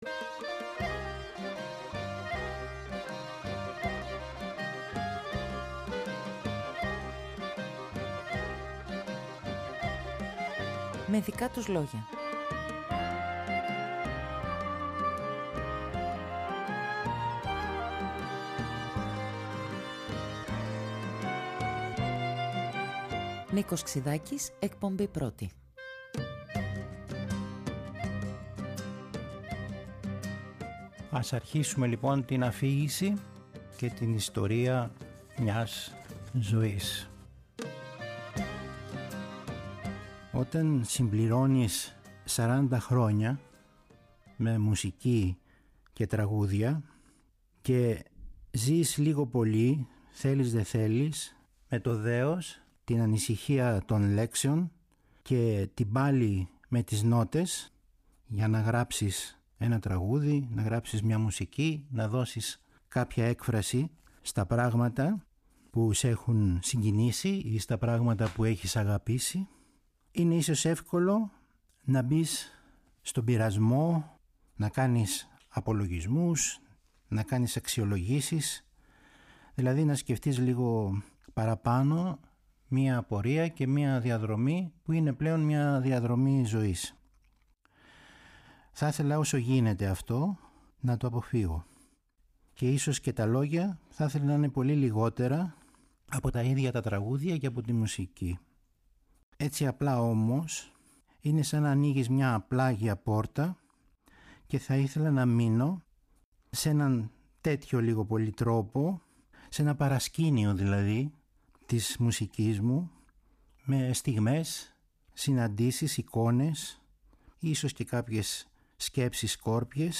Πρόσωπα του πενταγράμμου αφηγούνται τη δική τους ιστορία…
Τα πρώτα χρόνια στο Κάιρο, η επάνοδος στην Ελλάδα, ο Ντοστογιέφσκι, ο Νίκος Εγγονόπουλος, ο Μανόλης Ρασούλης, η «Βενετσιάνα», η «Εκδίκηση της Γυφτιάς» Τις Κυριακές του Δεκεμβρίου ο Νίκος Ξυδάκης αφηγείται τη δική του ιστορία..